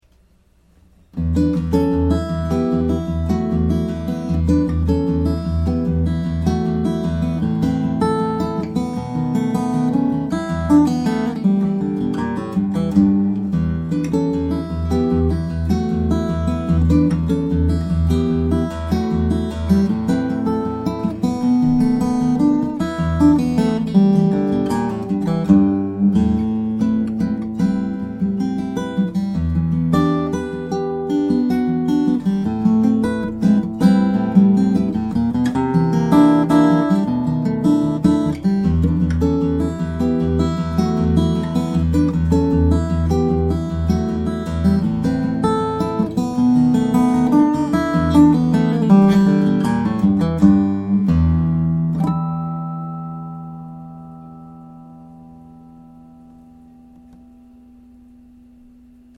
The Barndance is his Mahogany/Adirondack slope D design that boasts amazing power and projection.